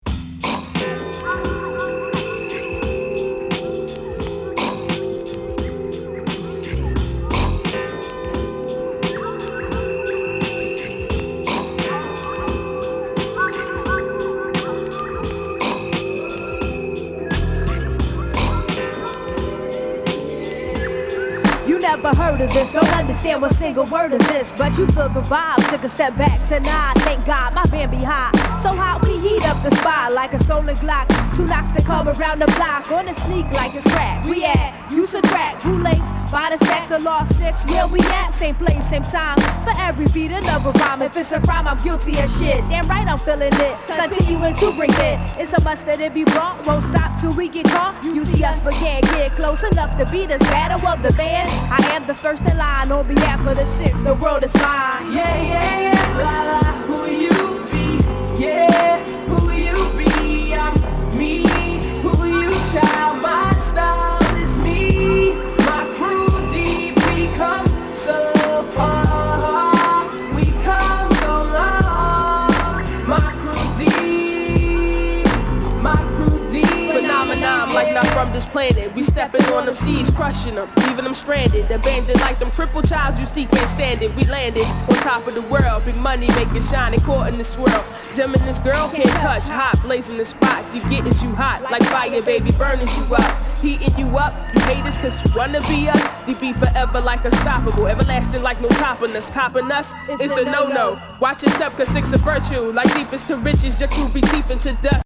当前位置 > 首页 >音乐 >唱片 >R＆B，灵魂